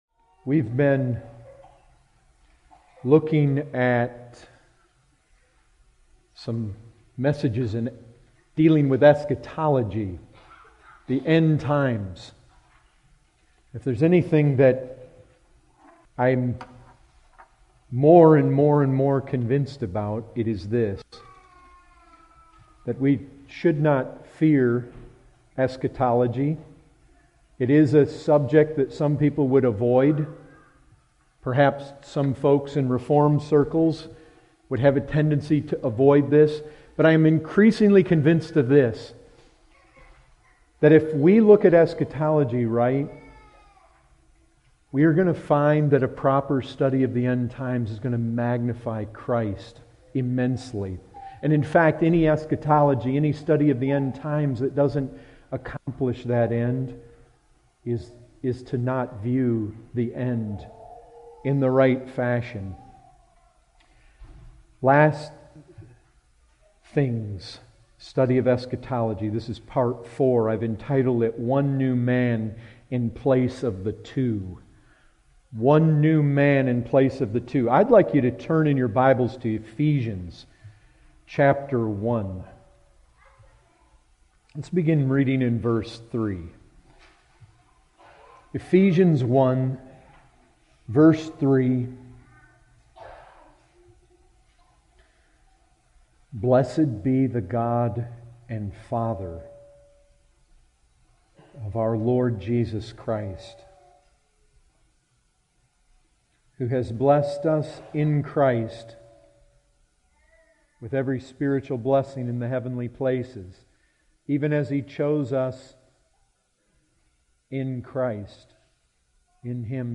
2015 Category: Full Sermons Topic